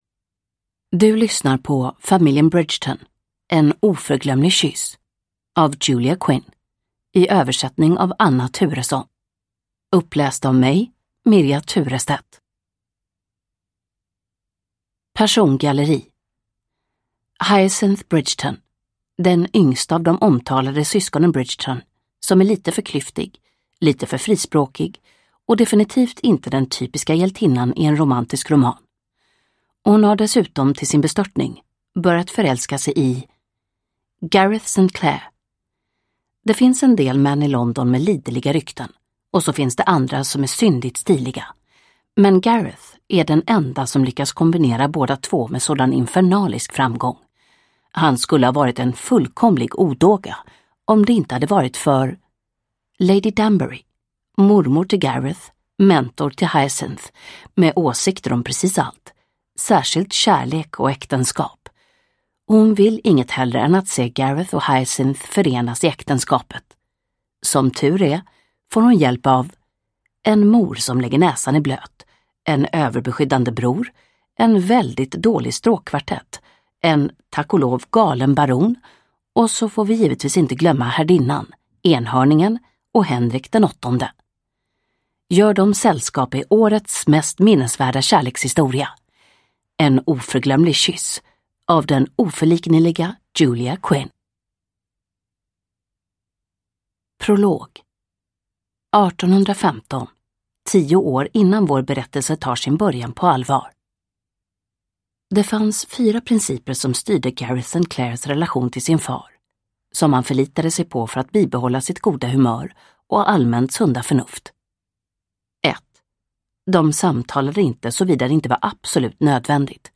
En oförglömlig kyss – Ljudbok – Laddas ner